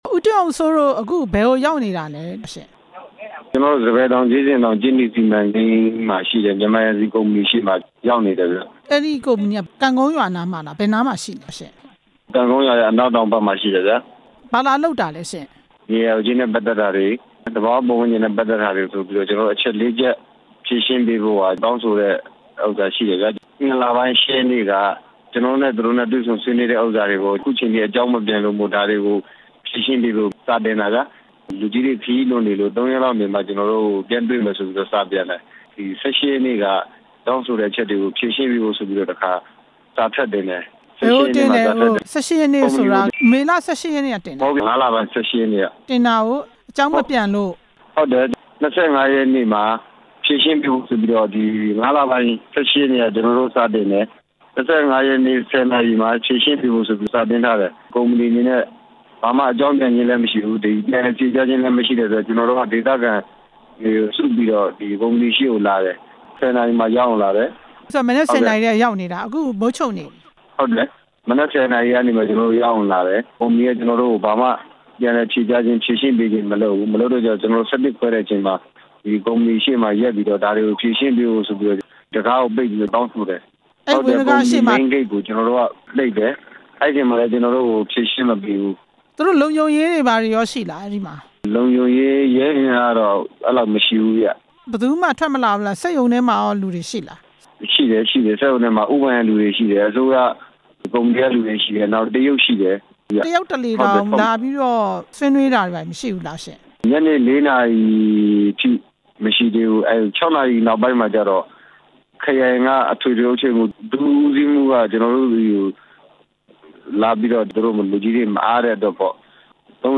ကြေးနီစီမံကိန်းကို ဒေသခံတွေ ဆန္ဒပြတဲ့အကြောင်း မေးမြန်းချက်